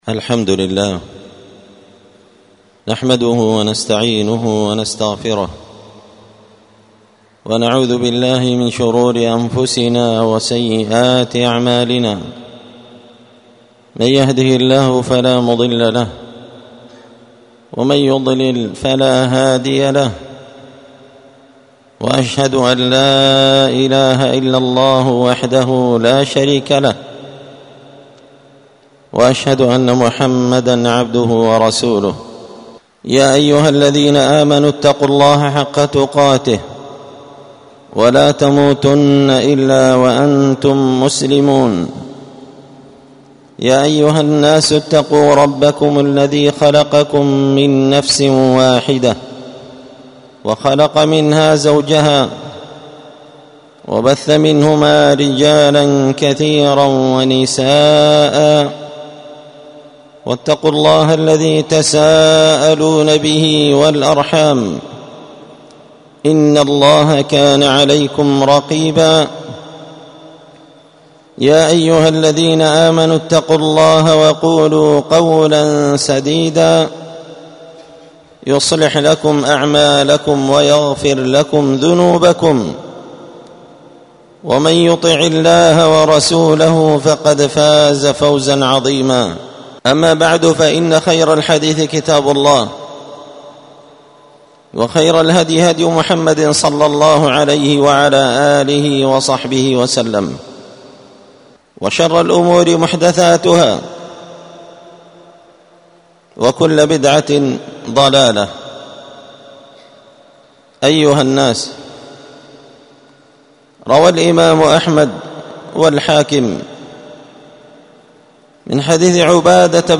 خطبة جمعة
ألقيت هذه الخطبة بدار الحديث السلفية بمسجد الفرقان قشن -المهرة-اليمن